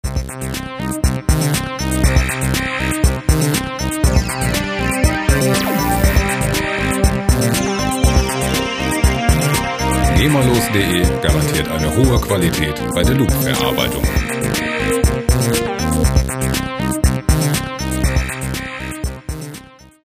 • Electro Wave